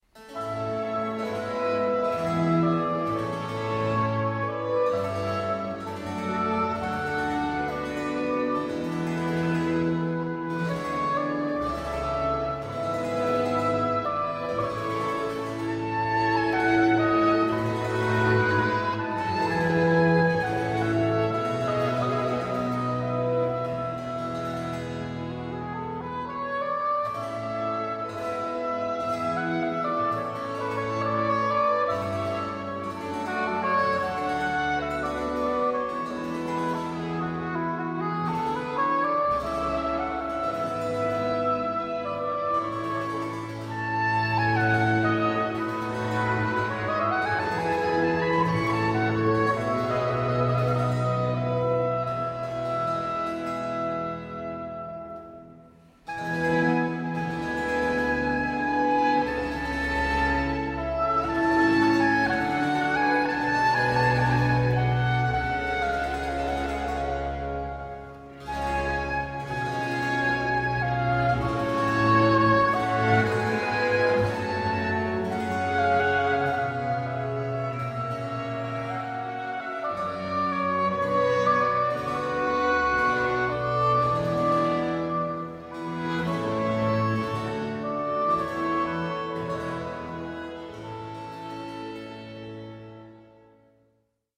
g-Moll für Oboe, Streicher und Basso continuo